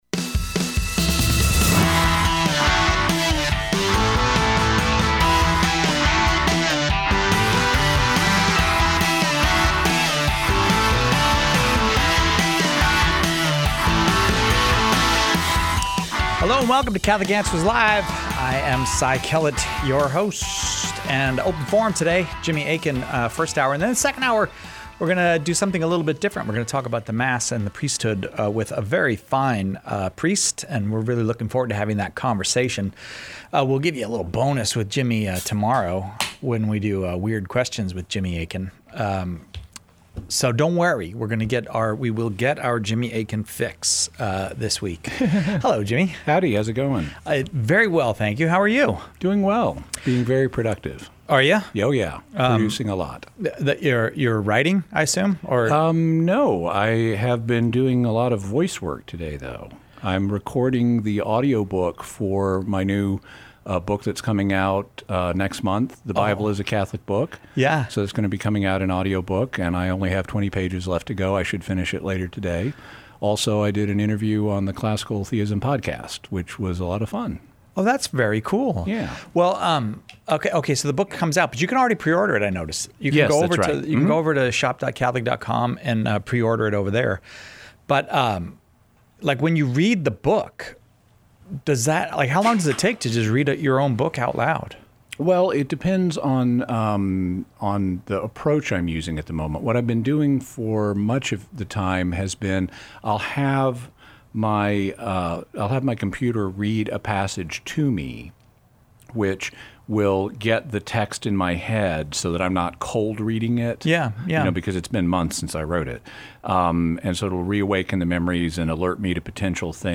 On open forum days, every caller with a question about the Catholic faith is welcome.